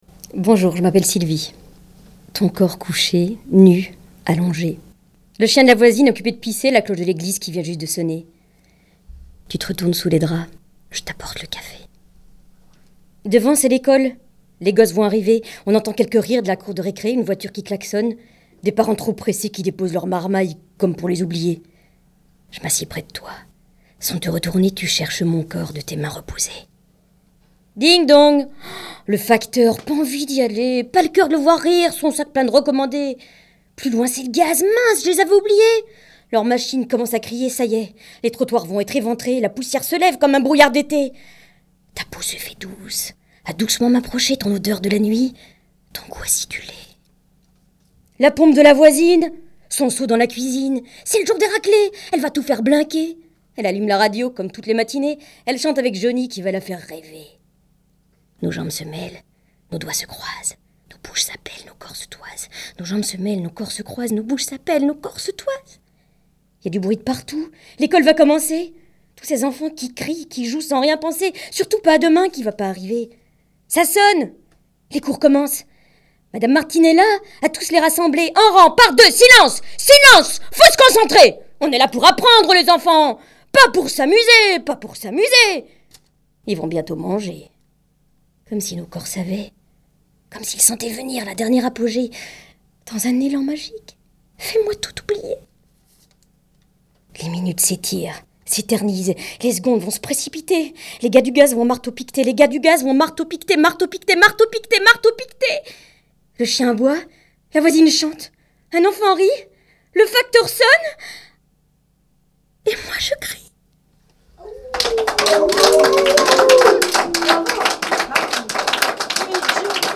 ateliers slam , écriture et enregistrement de séquences
séquence slam 9